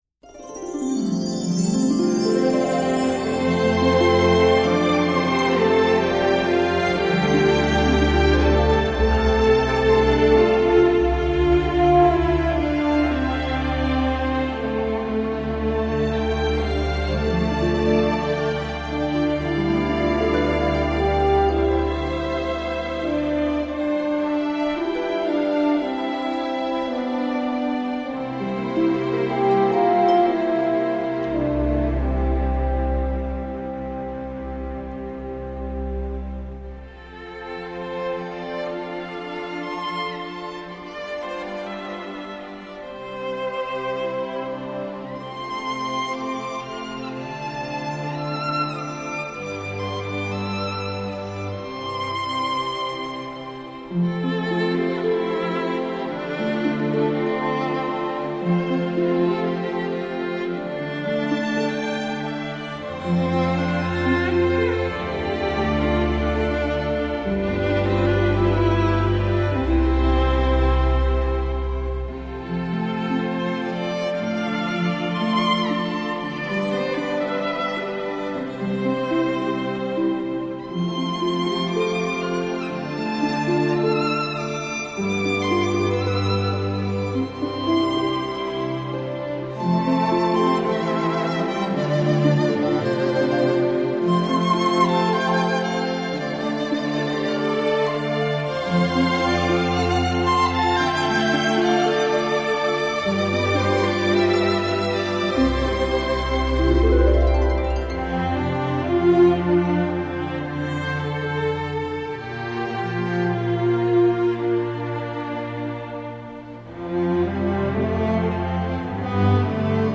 类别:电影原声
这段音乐很特别，是可以独立欣赏的一段小提琴协奏曲型式管弦乐。先以竖琴奏出幻境般的音色，随之富江南特色的旋律拉开场景
本曲第一主题也恰好跨十三度，同样带给人心胸为之一宽的畅快感。